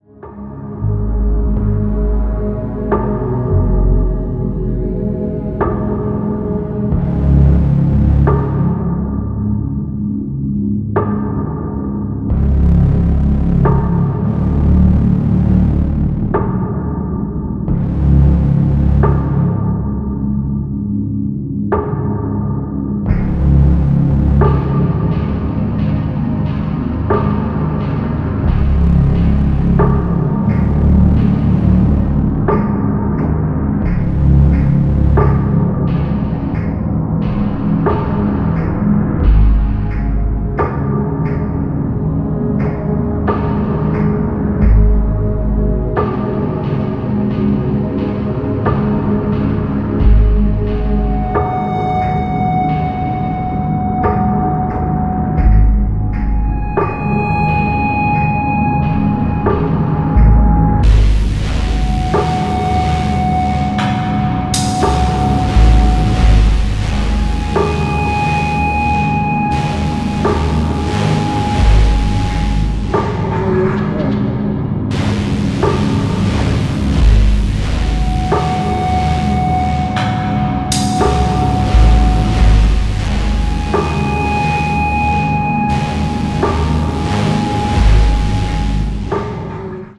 dub-heavy, adventurous electronic music